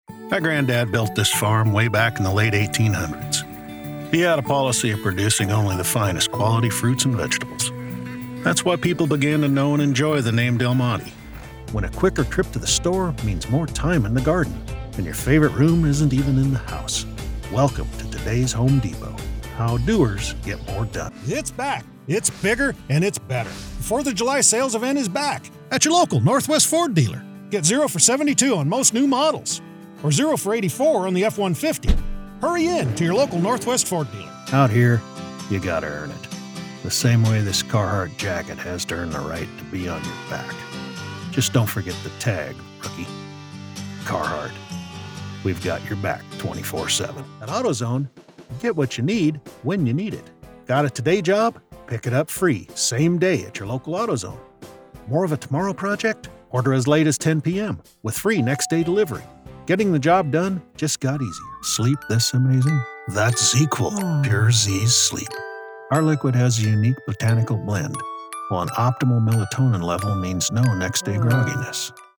Adult (30-50) | Older Sound (50+)
0923Commercial_demo.mp3